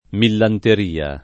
millanteria [ millanter & a ] s. f.